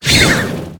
Cri de Lapyro dans Pokémon HOME.